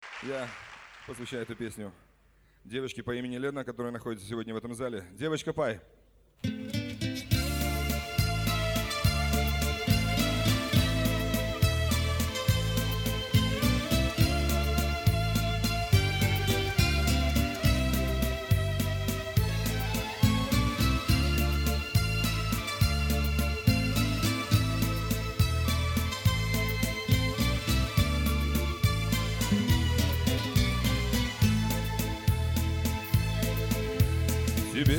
Chanson
Жанр: Русский поп / Русский рэп / Русский шансон / Русские